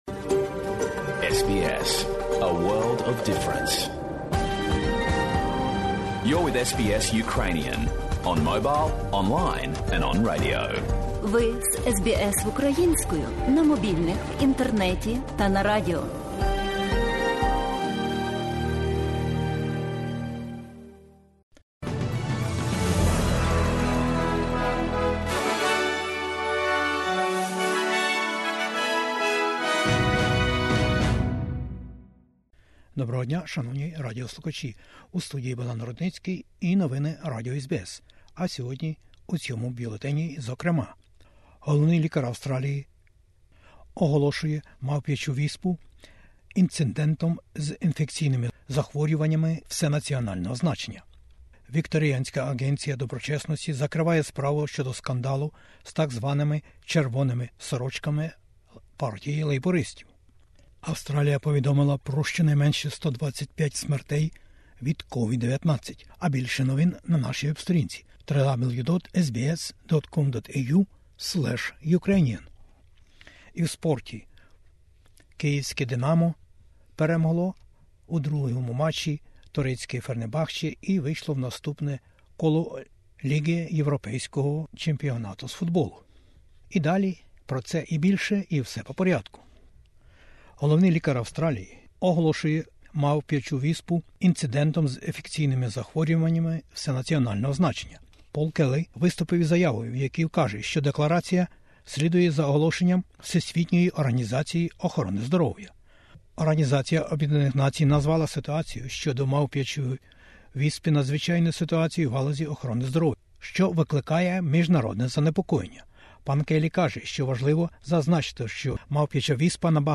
Бюлетень SBS новин українською мовою. COVID-19 і мавпячз віспа - повідомлення і застереження головного лікаря Австралії опісля даних ВООЗ. Інфляція і сповільнення економіки Австралії.